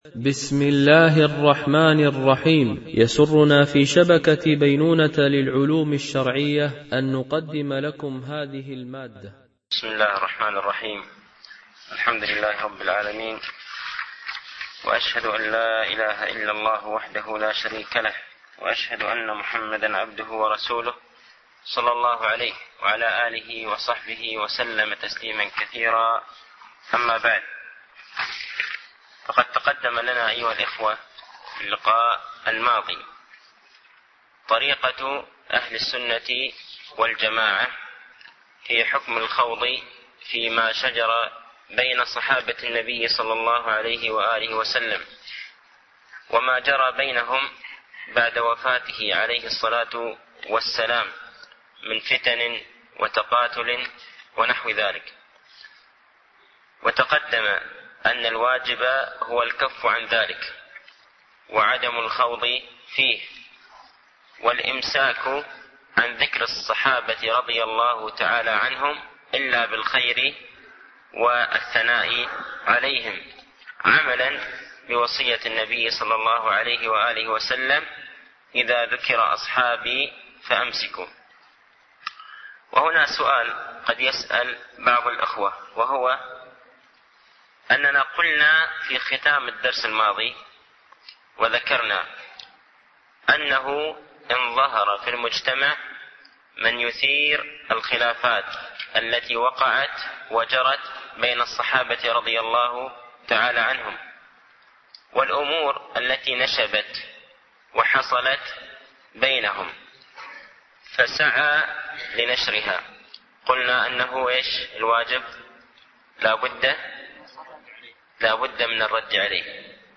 ) الألبوم: شبكة بينونة للعلوم الشرعية التتبع: 136 المدة: 48:46 دقائق (11.2 م.بايت) التنسيق: MP3 Mono 22kHz 32Kbps (CBR)